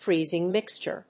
音節fréezing mìxture